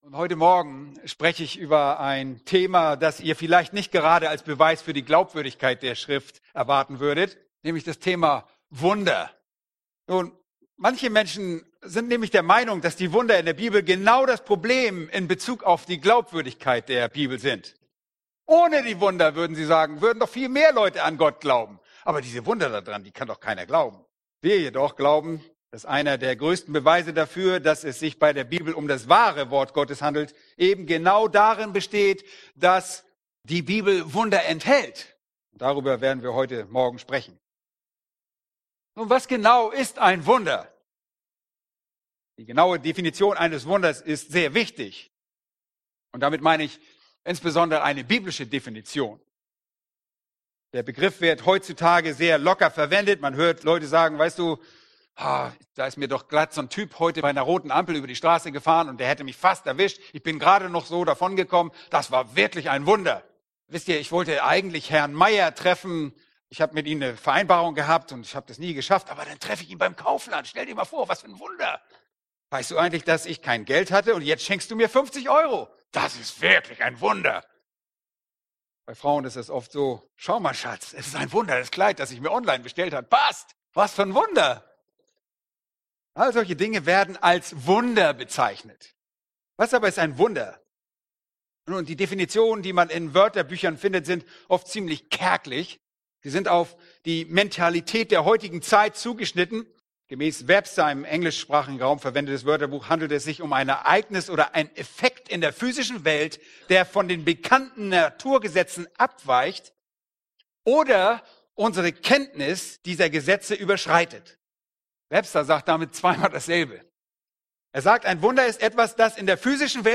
Aus der Serie: Ist die Bibel glaubwürdig?* | Weitere Predigten